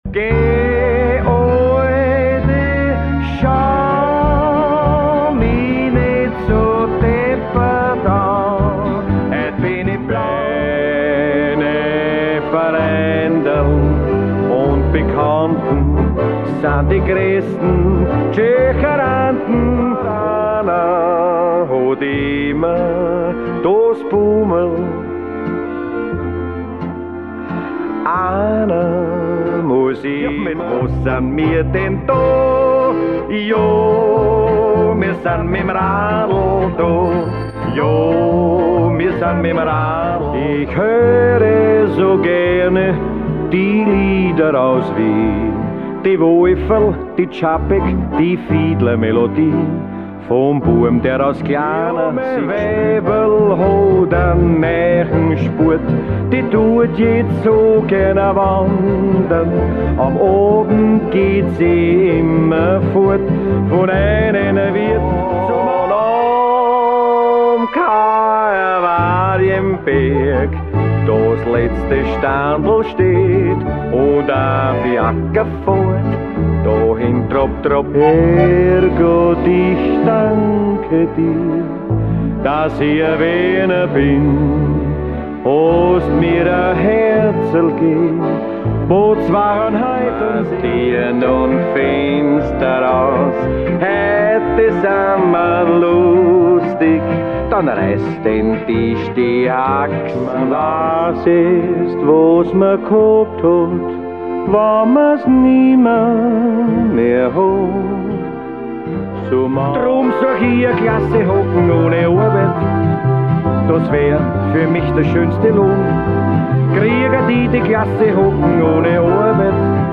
Ein bunter Reigen an Wienermusik
Hier hören Sie einen kurzen Ausschnitt aus jedem Titel